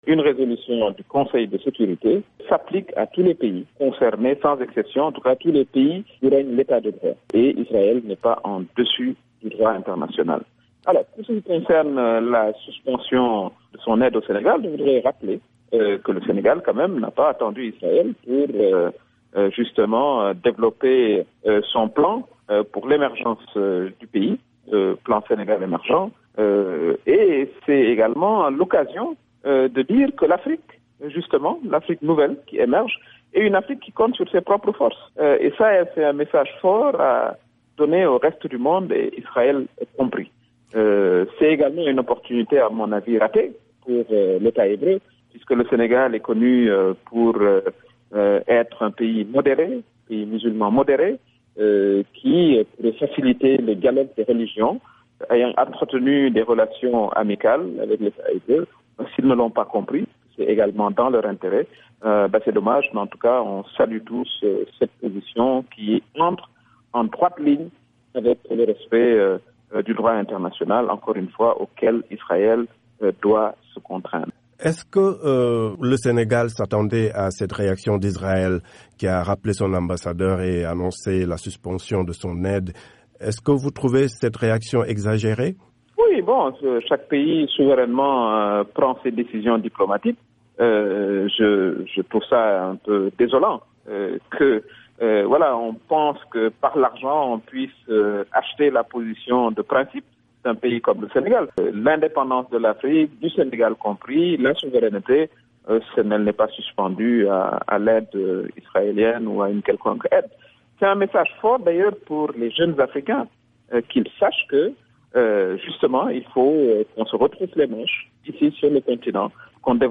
L’envoyée spéciale de Macky Sall